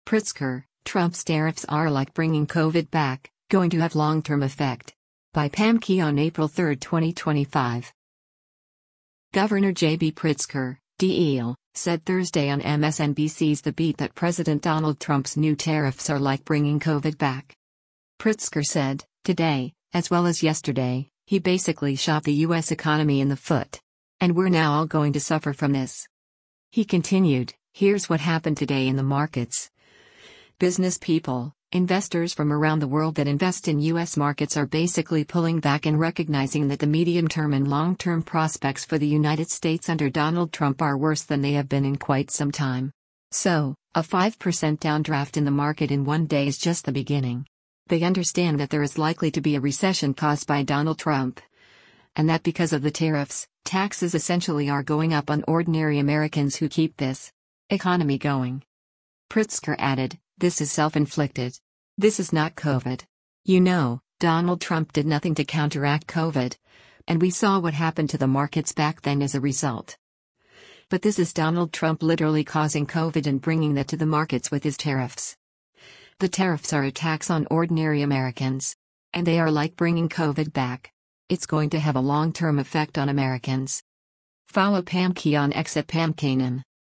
Governor JB Pritzker (D-IL) said Thursday on MSNBC’s “The Beat” that President Donald Trump’s new tariffs are “like bringing COVID back.”